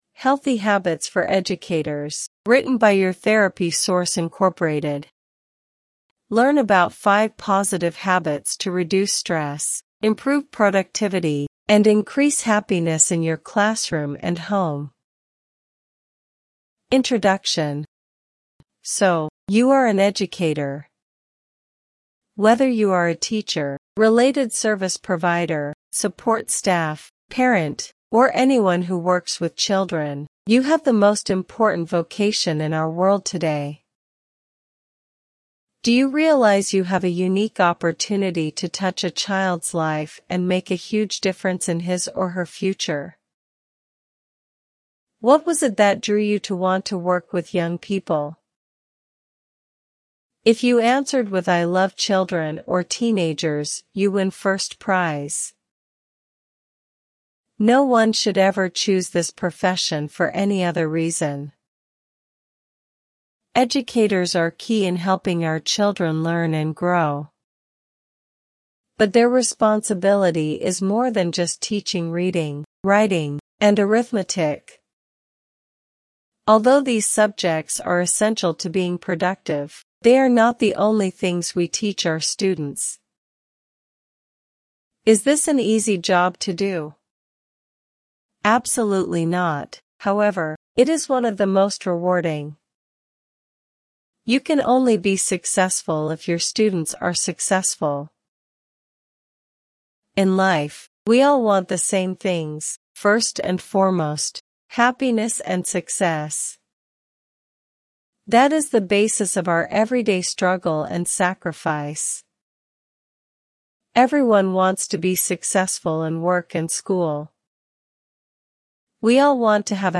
SAMPLE-AUDIOBOOK-Healthy-Habits-for-Educators.mp3